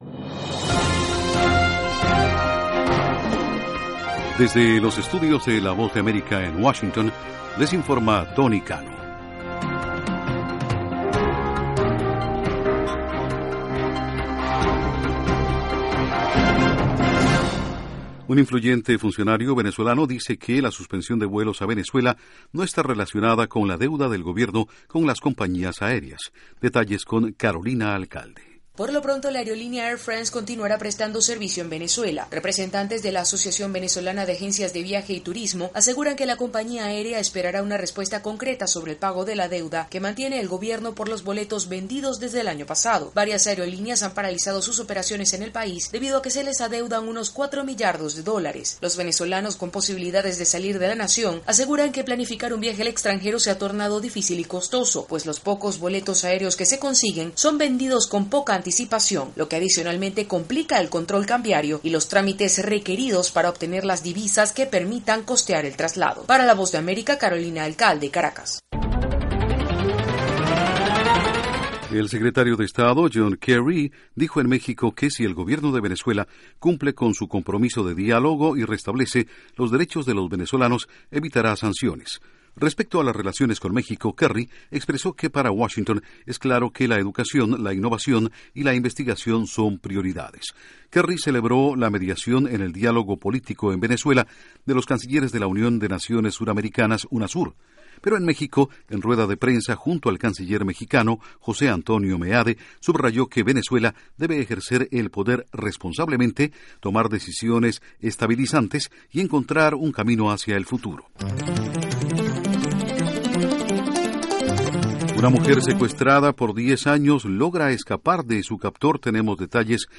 Noticias de la Voz de América, desde Washington